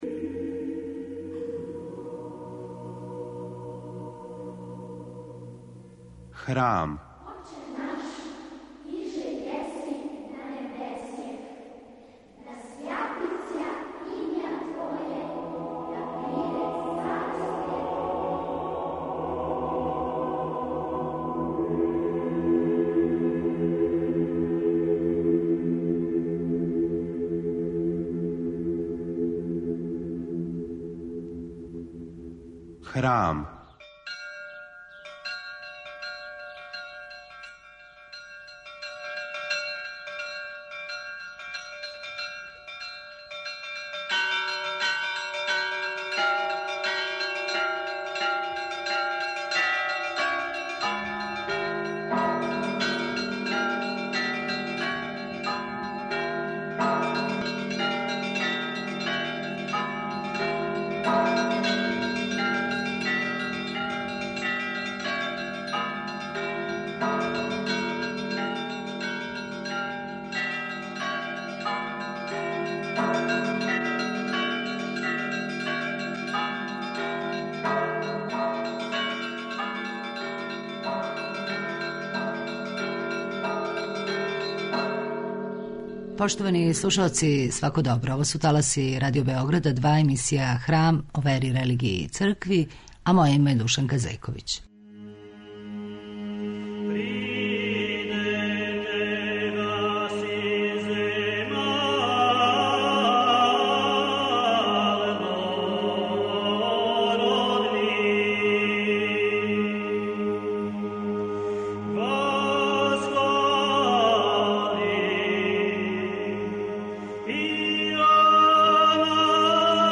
Емисија о вери, религији, цркви...